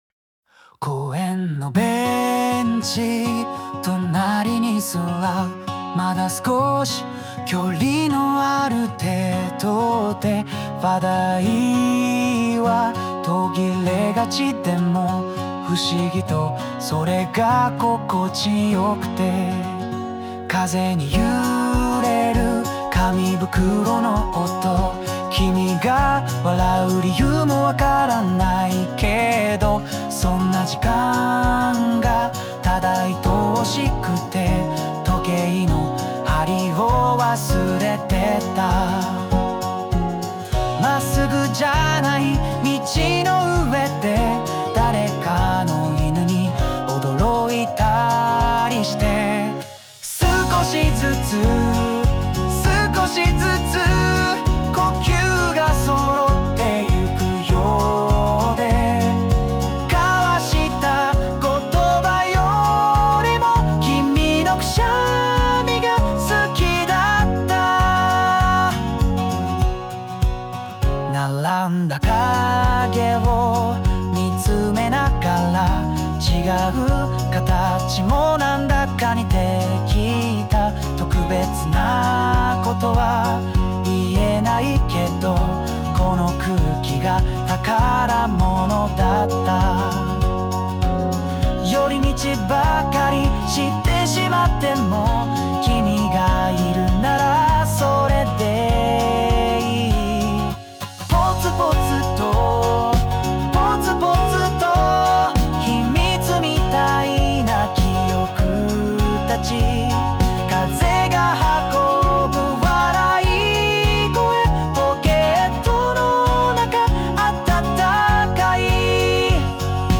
邦楽男性ボーカル著作権フリーBGM ボーカル
著作権フリーオリジナルBGMです。
男性ボーカル（邦楽・日本語）曲です。